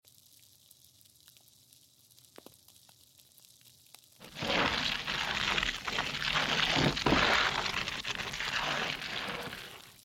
Hypnotic Macro ASMR